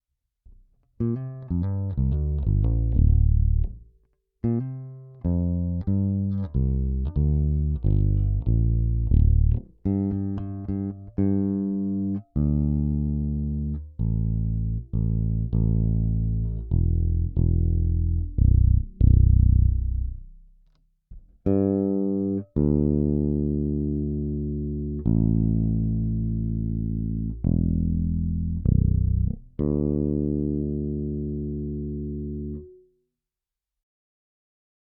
DR struny problém vyměněné A